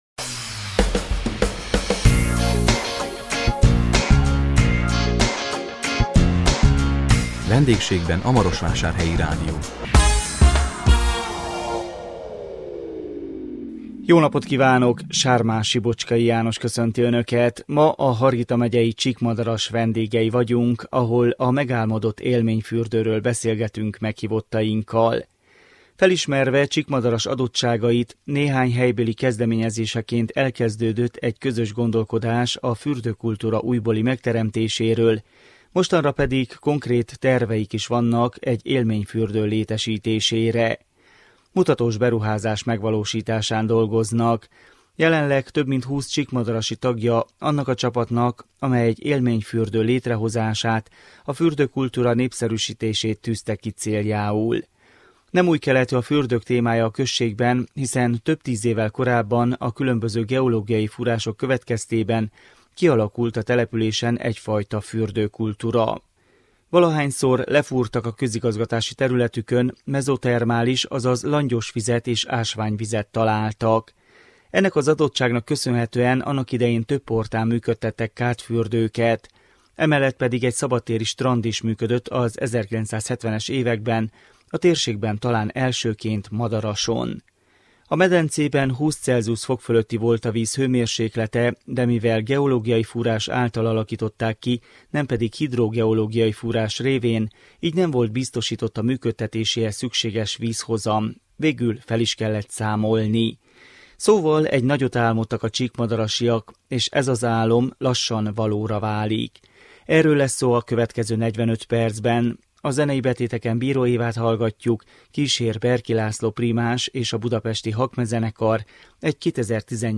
A 2018 október 11-én jelentkező Vendégségben a Marosvásárhelyi Rádió című műsorunkban a Hargita megyei Csíkmadaras vendégei voltunk, ahol a megálmodott élményfürdőről beszélgettünk meghívottainkkal. Felismerve Csíkmadaras adottságait, néhány helybéli kezdeményezéseként elkezdődött egy közös gondolkodás a fürdőkultúra újbóli megteremtéséről, mostanra pedig konkrét terveik is vannak egy élményfürdő létesítésére.